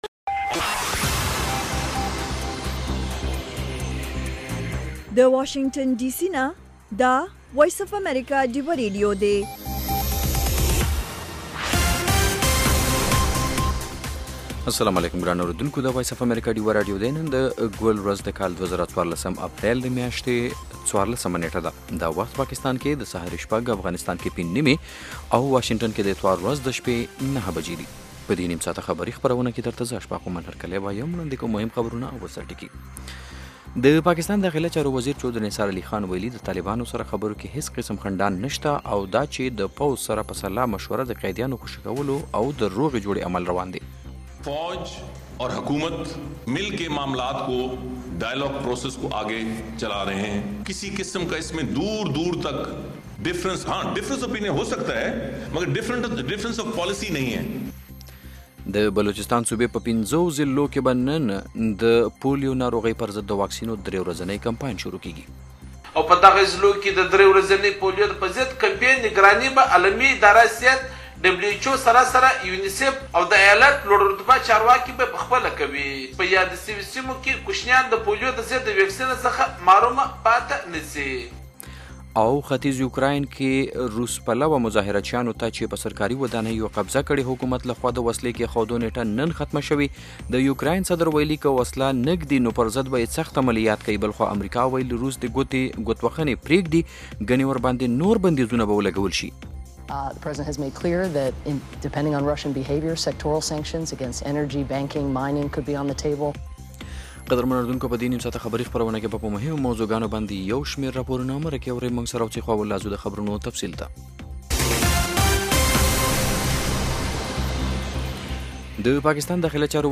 خبرونه - 0100
د وی او اې ډيوه راډيو سهرنې خبرونه چالان کړئ اؤ د ورځې د مهمو تازه خبرونو سرليکونه واورئ.